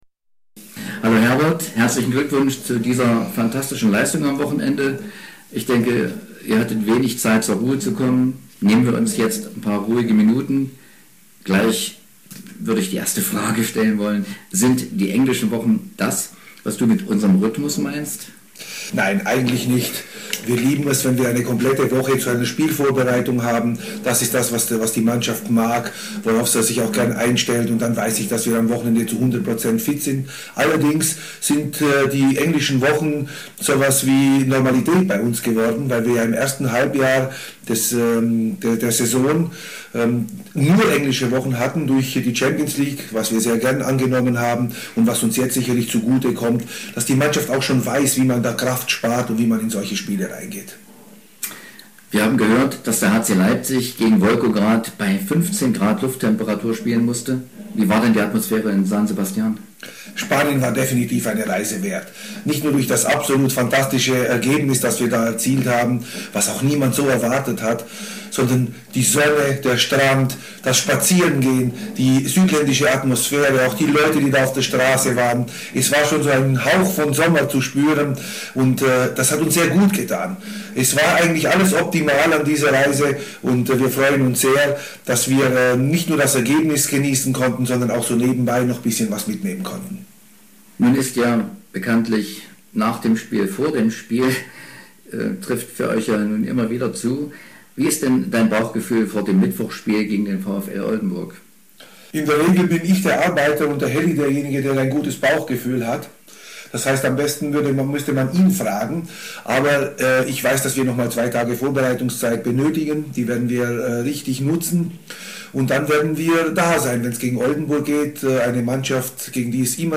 Im Gespräch mit unserer Redaktion